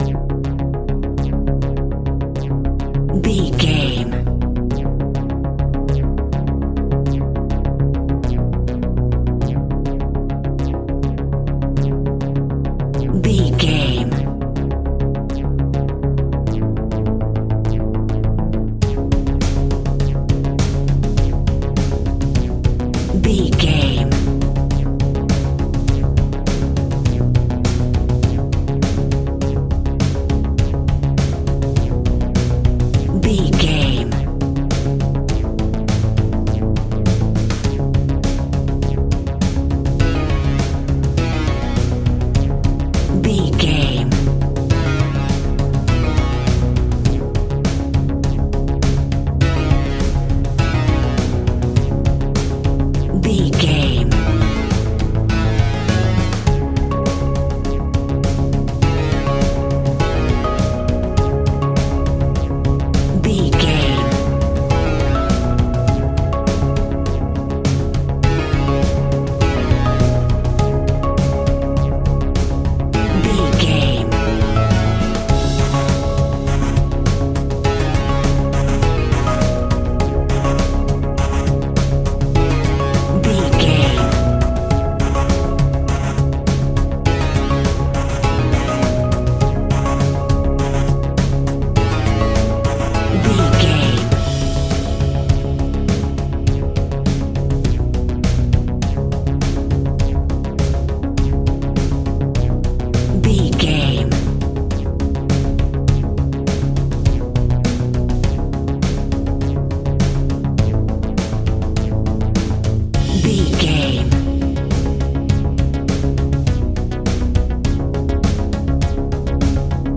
Aeolian/Minor
D♭
piano
synthesiser